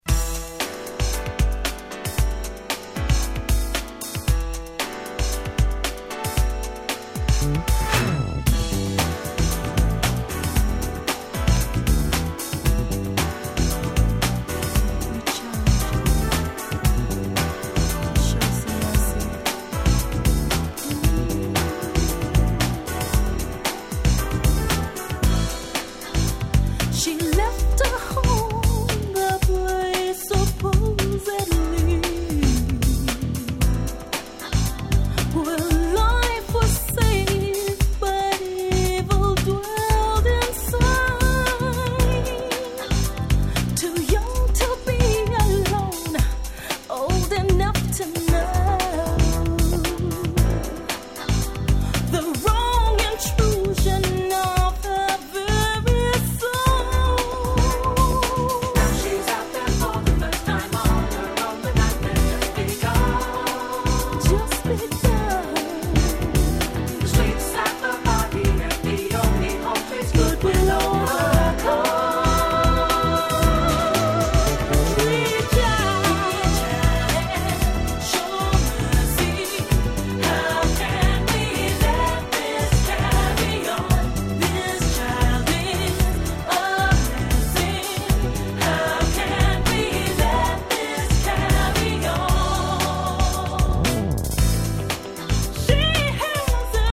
カップリングの爽快Acid Jazzナンバー